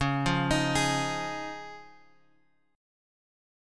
C#add9 chord {x 4 3 1 4 1} chord